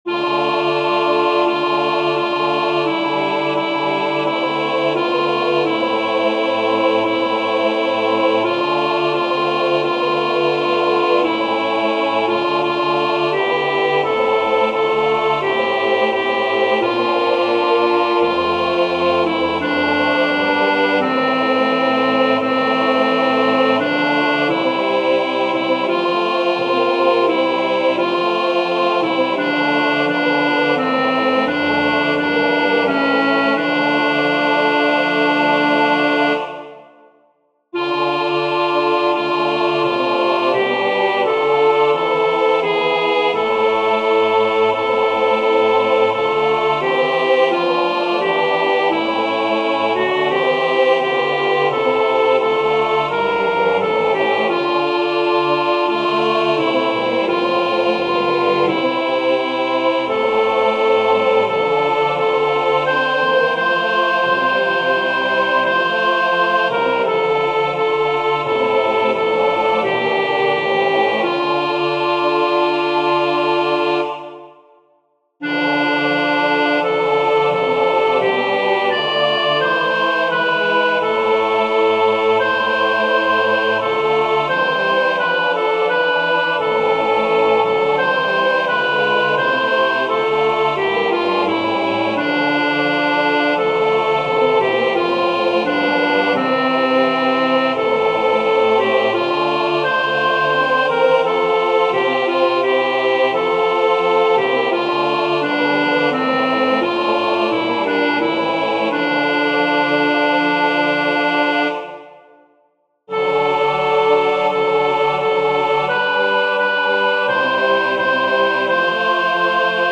Vers 2: mf. Vanaf maat 12: f.
Sopraan uitgelicht